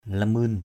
/la-mø:n/